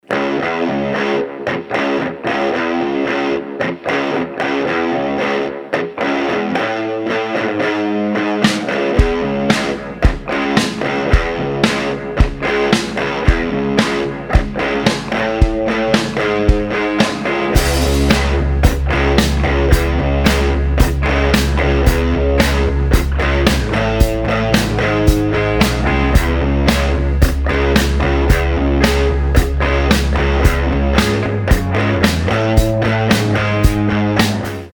гитара
без слов